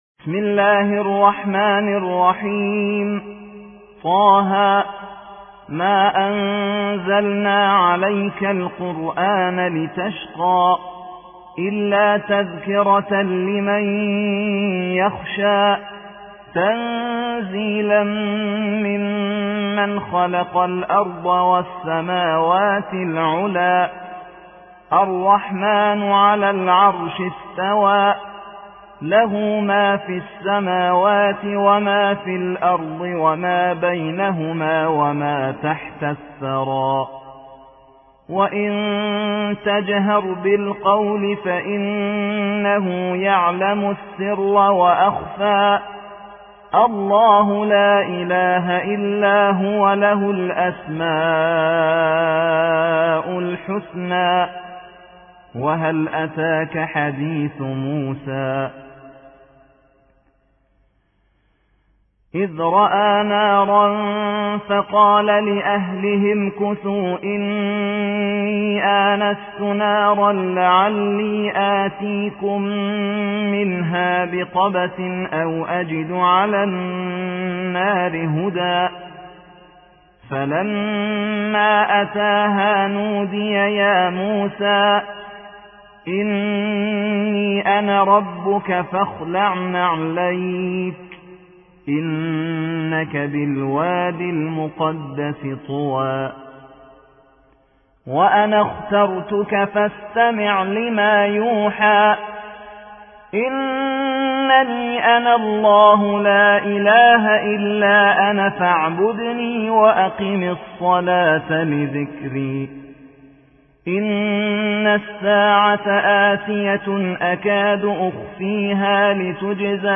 20. سورة طه / القارئ
القرآن الكريم